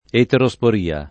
eterosporia [ etero S por & a ] s. f. (bot.)